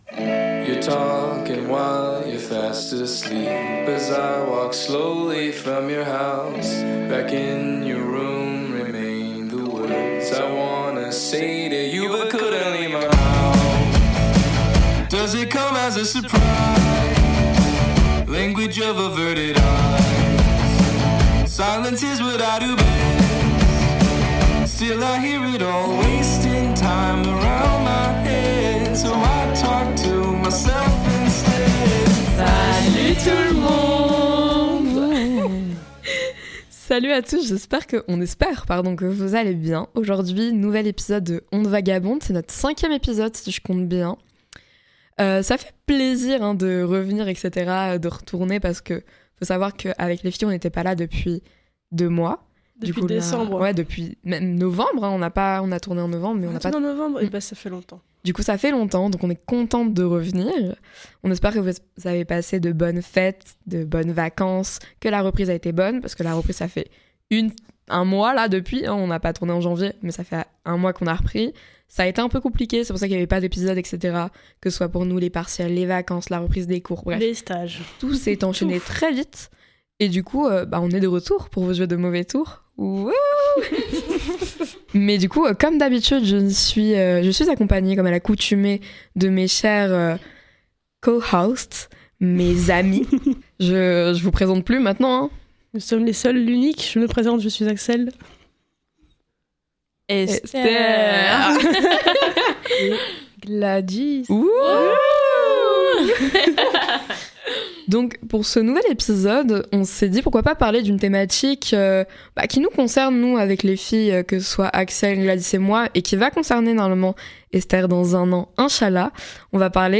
Le concept est simple : une discussion comme celle qu’on a avec ses potes tard dans la nuit, quand les masques tombent et qu’on laisse nos esprits vagabonder.
C’est un espace où l’on réfléchit, débat, on rigole (beaucoup aussi), tout en partageant nos perspectives sur ce qui nous intrigue ou nous passionne.